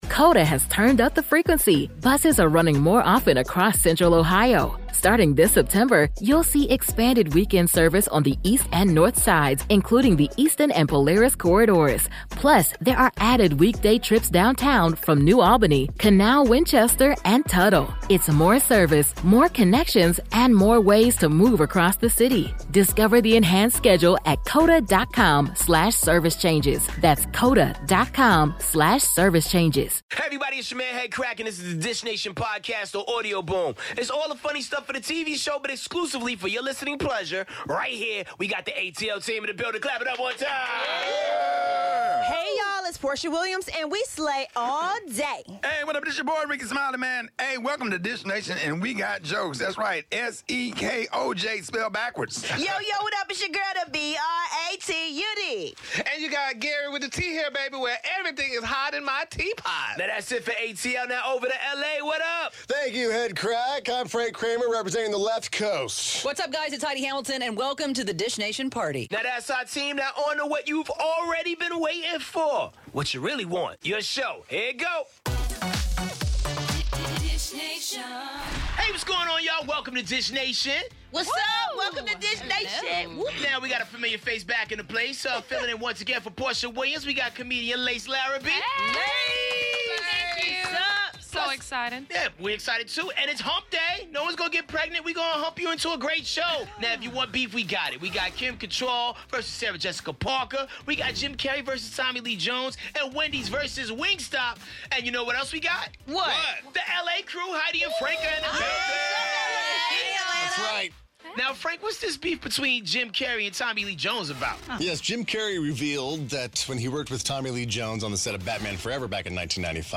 Guest host: comedian